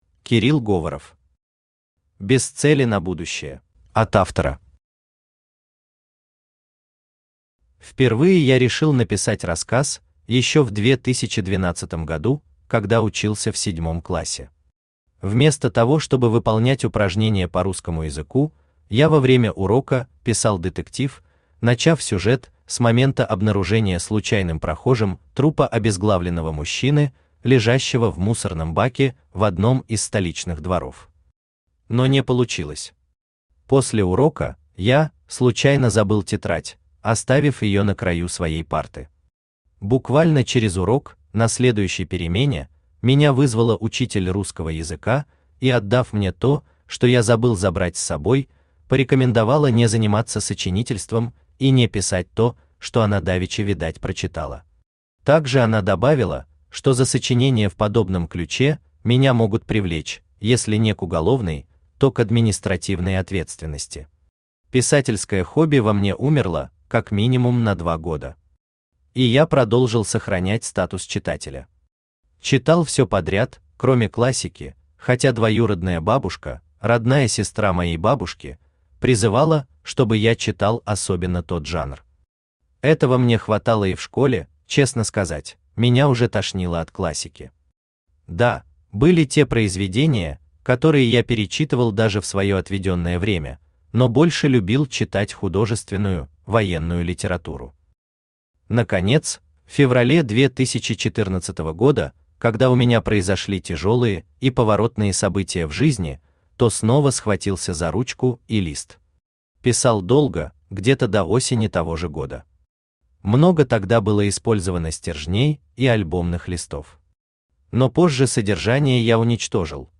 Аудиокнига Без цели на будущее | Библиотека аудиокниг
Aудиокнига Без цели на будущее Автор Кирилл Говоров Читает аудиокнигу Авточтец ЛитРес.